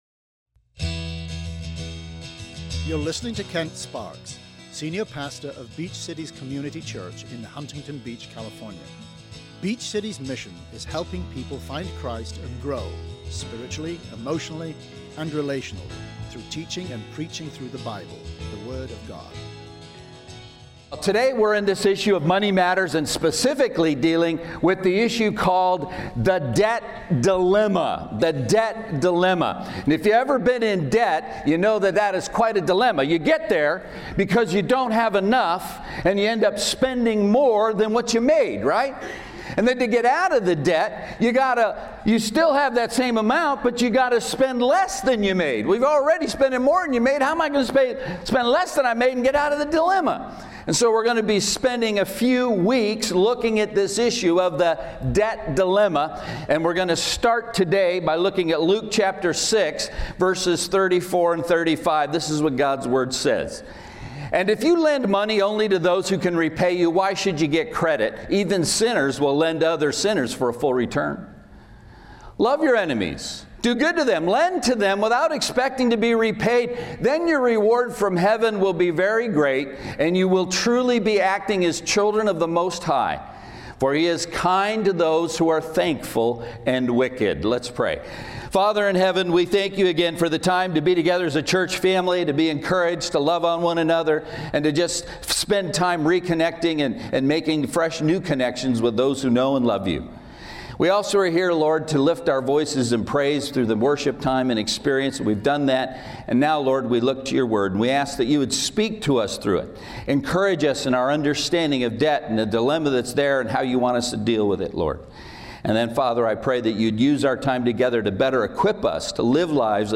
Listen in to the problem that our country faces with debt and how Americans personal debt has changed over the last 40 years. What does the bible say about debt? Find the seven principles in this message. SERMON AUDIO: SERMON NOTES: